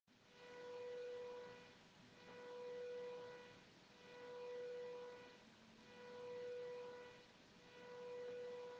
Забавно, что пульсация светодиодов дает наводку на динамик компьютера, который подключен в тот же хаб
На записи он гораздо громче, чем в жизни